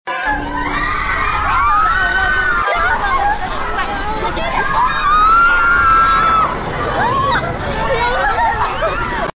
A Big Monster Walks On Sound Effects Free Download